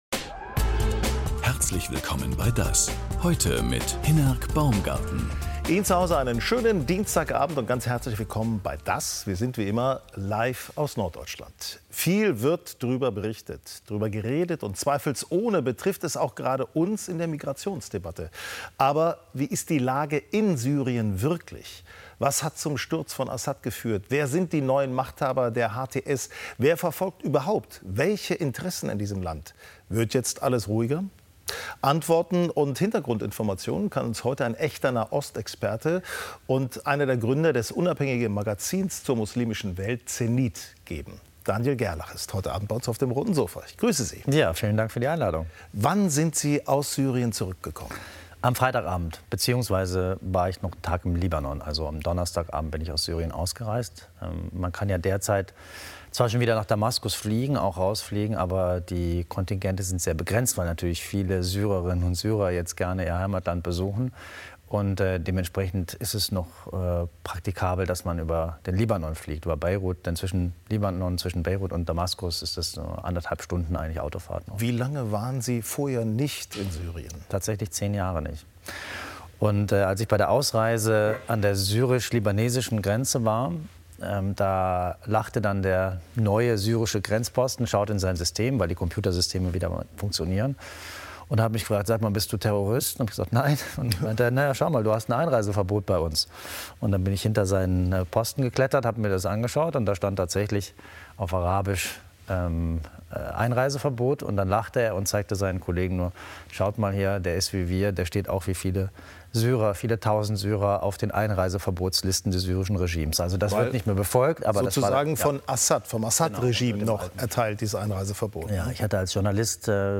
DAS! ist bekannt für intensive Interviews mit prominenten und kompetenten Gästen auf dem Roten Sofa. Die Gesprächsfassung (ohne Filmbeiträge) vom Vortag gibt es auch als Audio-Podcast.